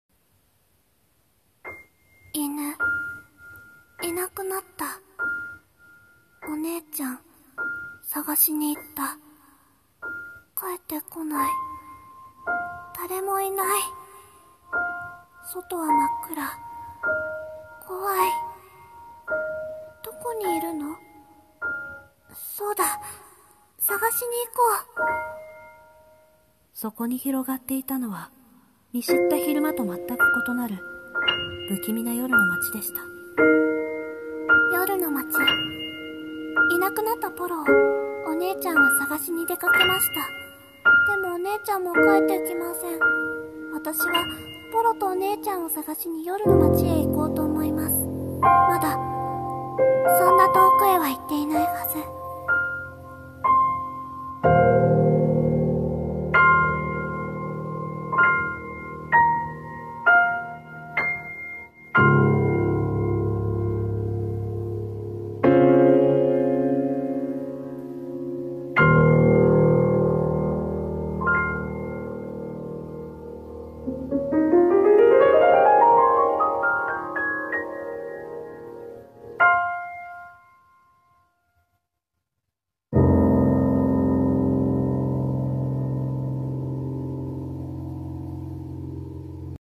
【声劇台本】夜廻～OP / 貴方の名前